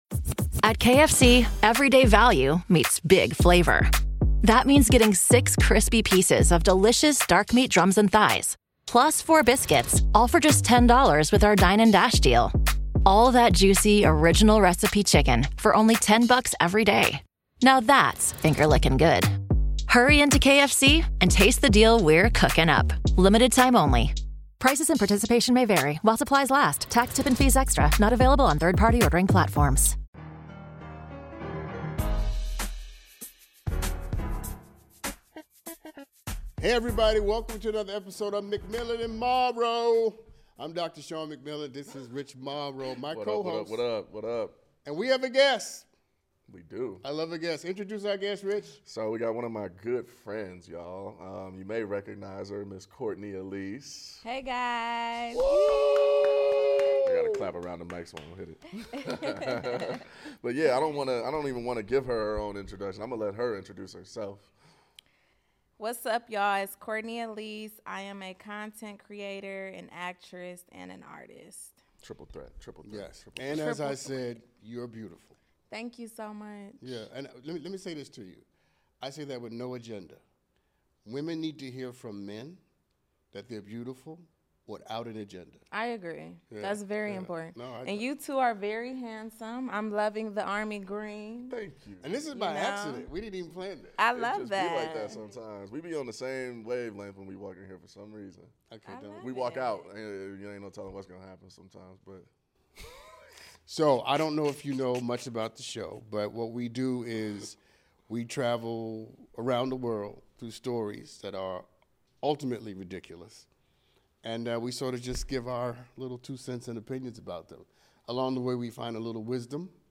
Actress and comedian